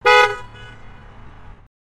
دانلود صدای بوق 5 از ساعد نیوز با لینک مستقیم و کیفیت بالا
جلوه های صوتی